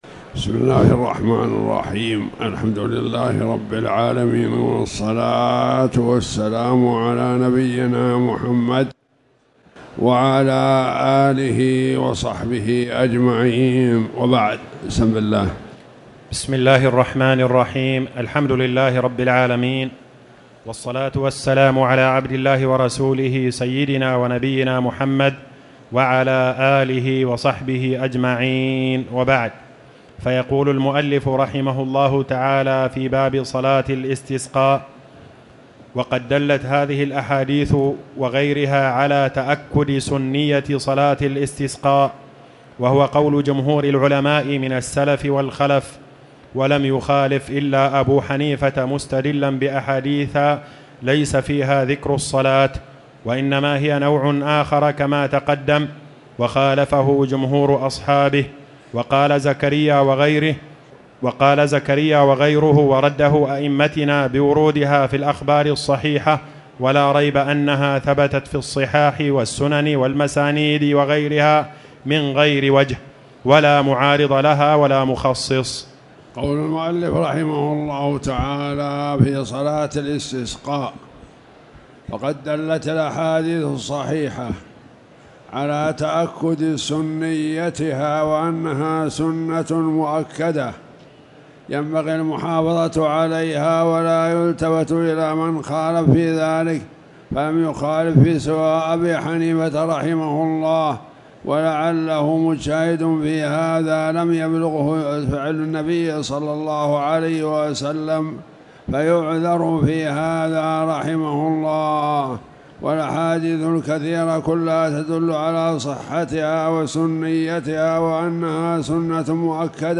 تاريخ النشر ١٨ رجب ١٤٣٨ هـ المكان: المسجد الحرام الشيخ